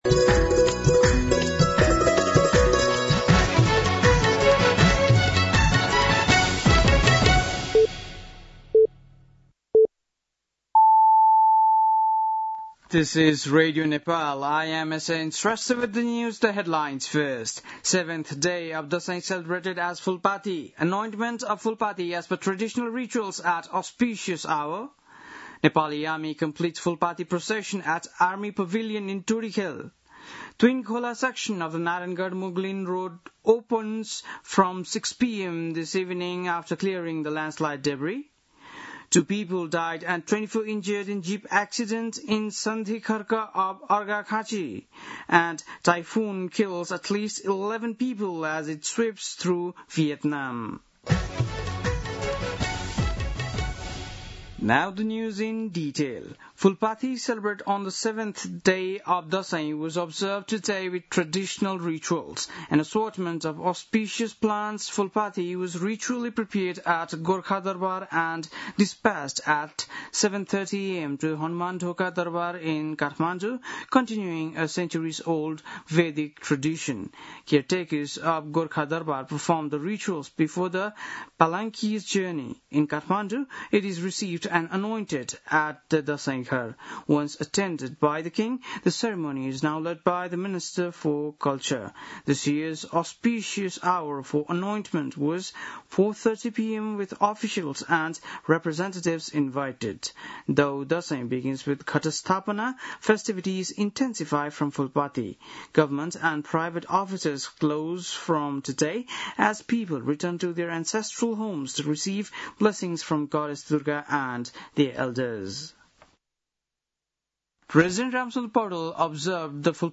बेलुकी ८ बजेको अङ्ग्रेजी समाचार : १३ असोज , २०८२